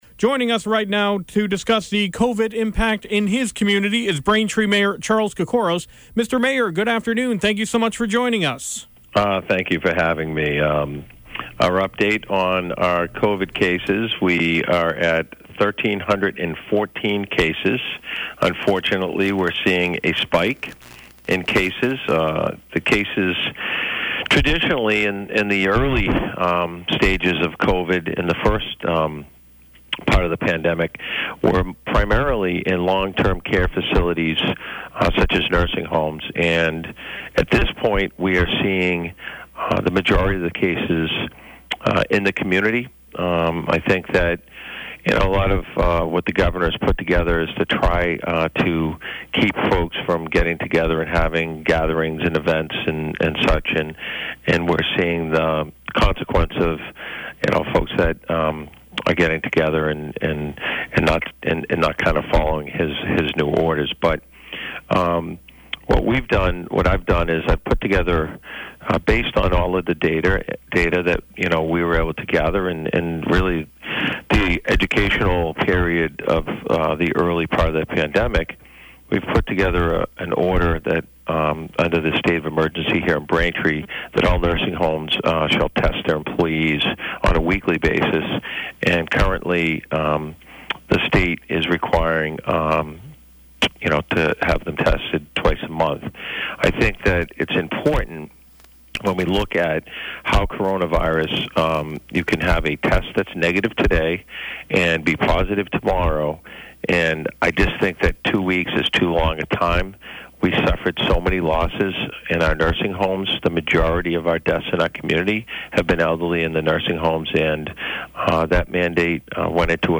Braintree Mayor Charles Kokoros discusses the latest COVID numbers and a new order regarding nursing homes in the town.